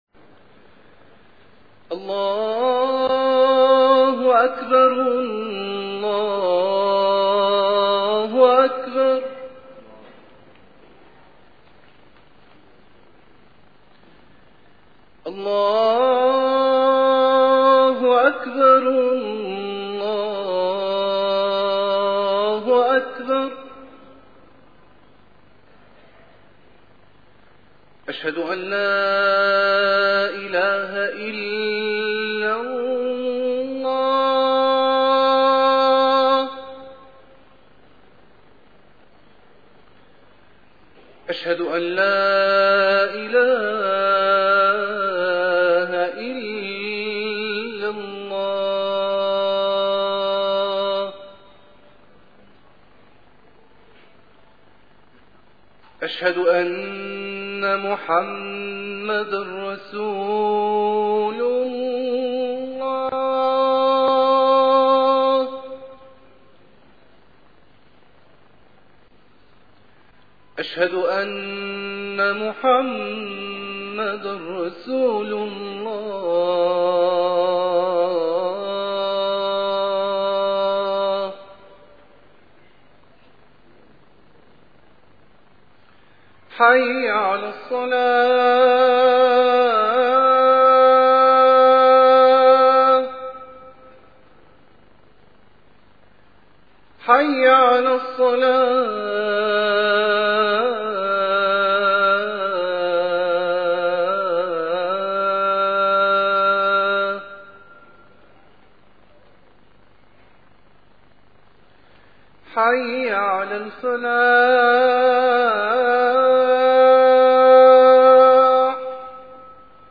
- الخطب - العمل الصالحِ